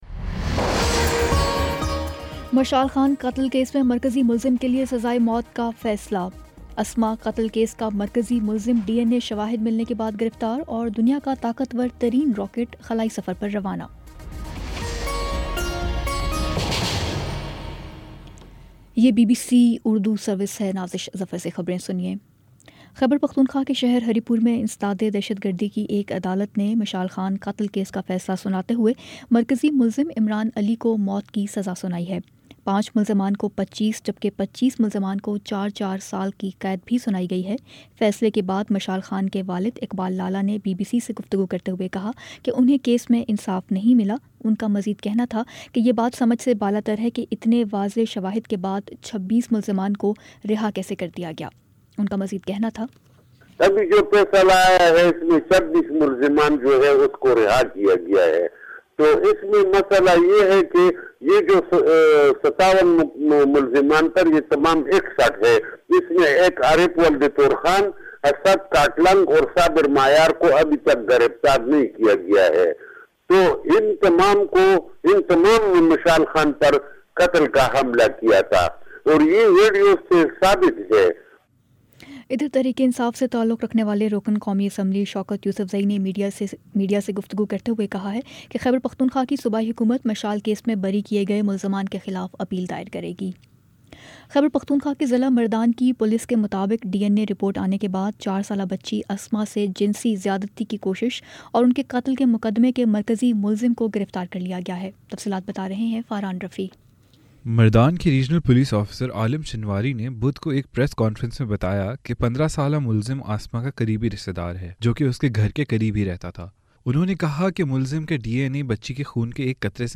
فروری 07 : شام چھ بجے کا نیوز بُلیٹن
دس منٹ کا نیوز بُلیٹن روزانہ پاکستانی وقت کے مطابق شام 5 بجے، 6 بجے اور پھر 7 بجے۔